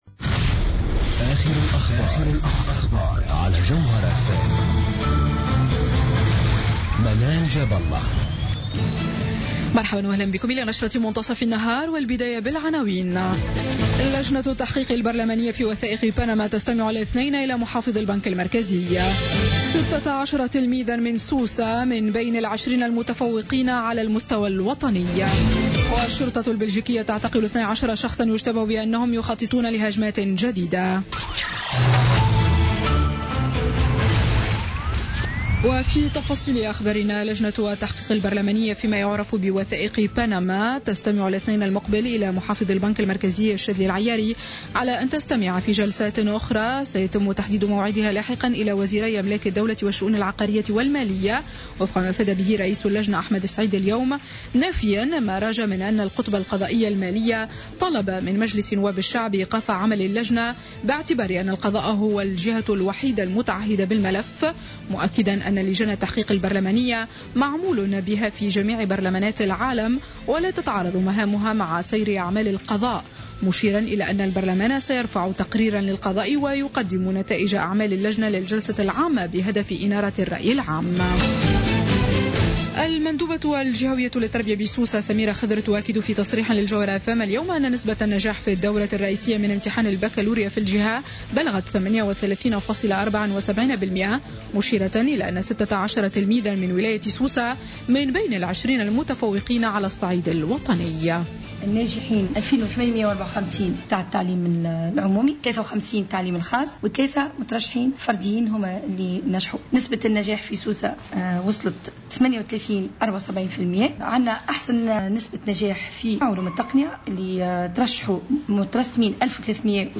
Journal Info 12h00 du samedi 18 Juin 2016